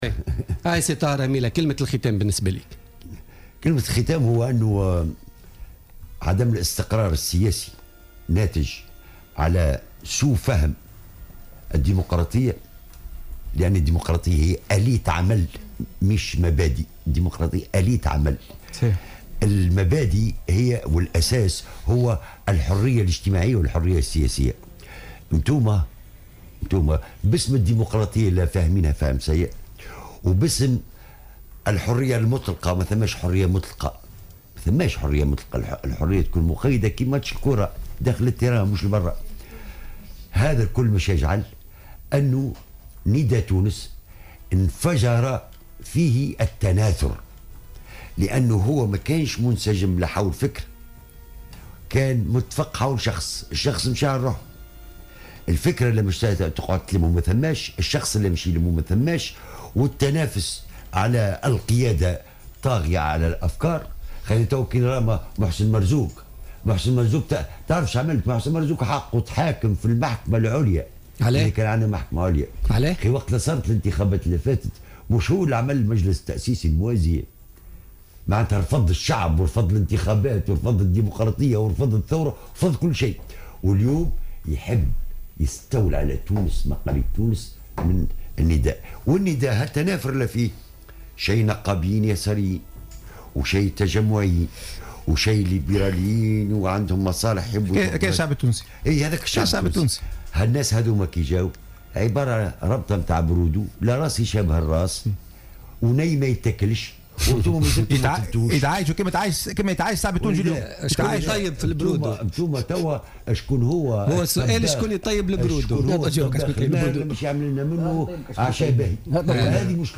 قال النائب السابق الطاهر هميلة ضيف بوليتيكا اليوم الإثنين 2 نوفمبر 2015 إن نداء تونس بفهمه السيء للديمقراطية انفجر فيه التناثر لأنه لم يكن منسجما حول فكرا معينا بل كان متفقا حول شخص ولكن ذلك الشخص ذهب على حد قوله.